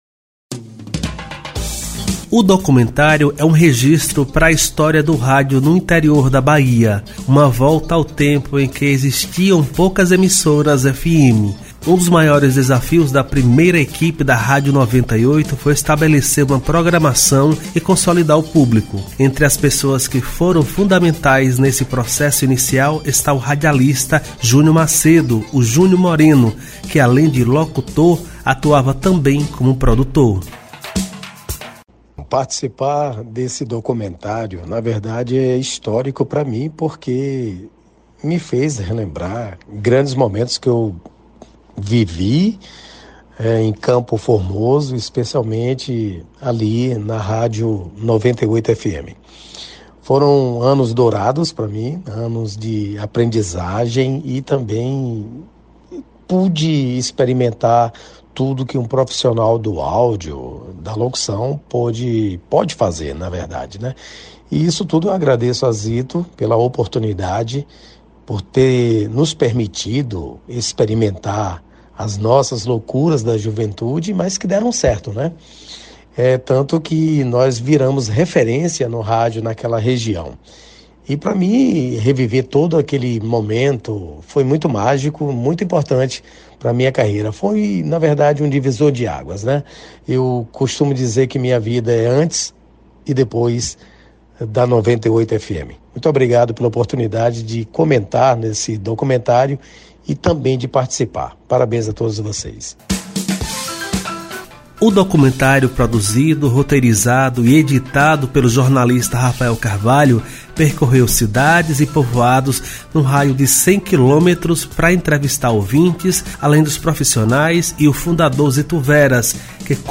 Entrevistas com radialistas que participam do documentário da 98 FM lançado nos 34 anos da emissora